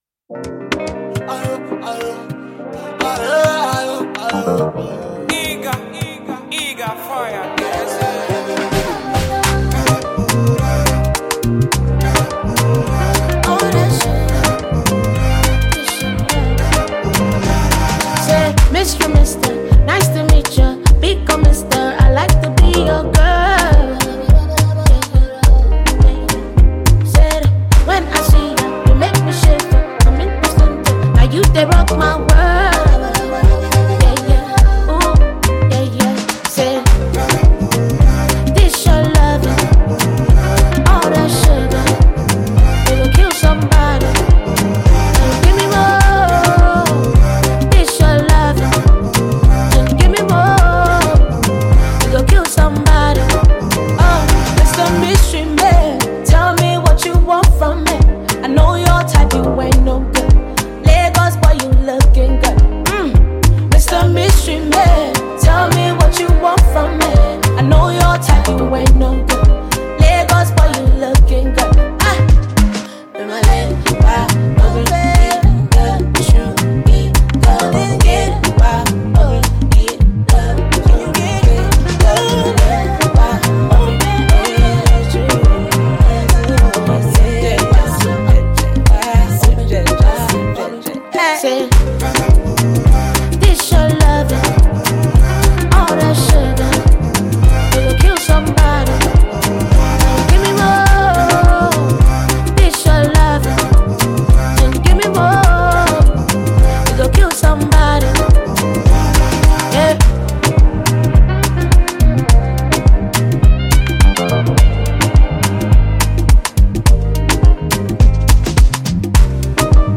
A Sensational Blend of Afrobeat and Soul